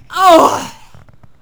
technician_die3.wav